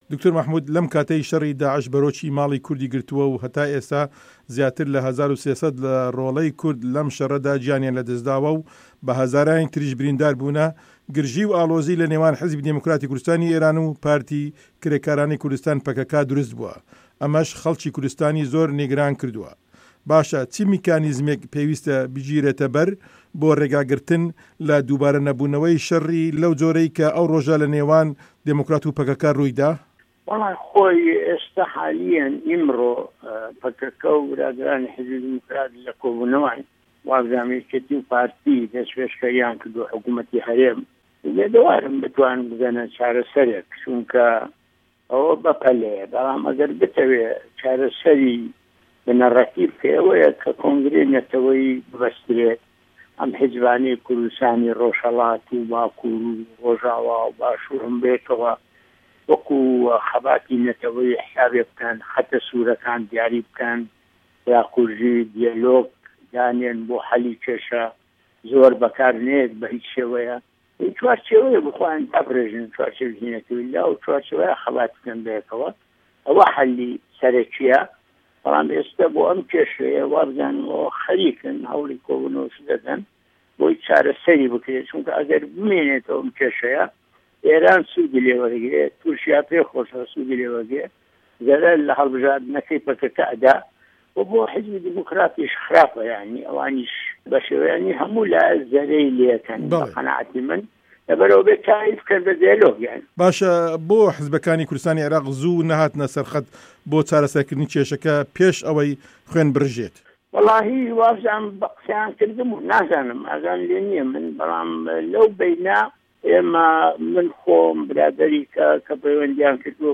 وتووێژی دکتۆر مه‌حمود عوسمان